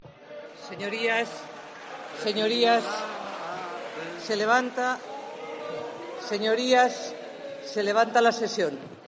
Concluido el pleno, Pastor ha acompañado al presidente portugués a la salida, mientras los diputados seguían cantando el estribillo de la canción.